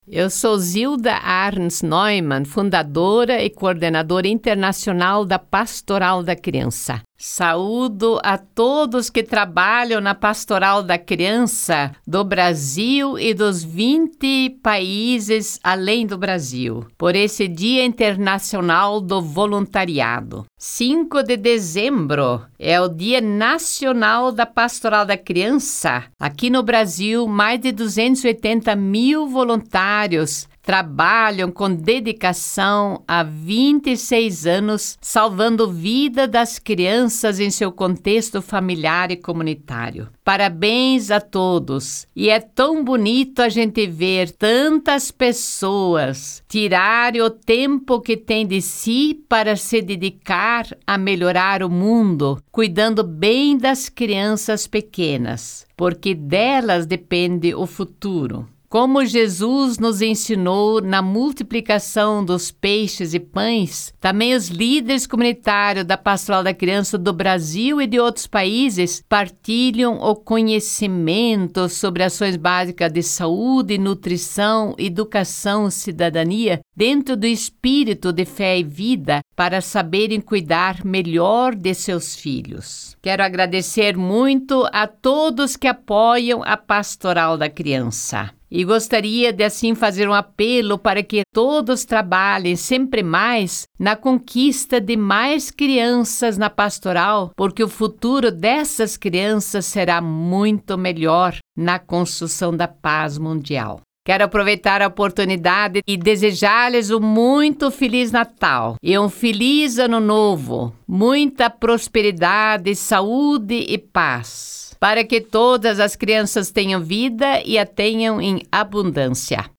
Para marcar esse dia tão especial, Dra. Zilda Arns Neumann gravou uma mensagem de motivação e agradecimento pelo trabalho dedicado por tantos voluntários em todos os lugares do Brasil.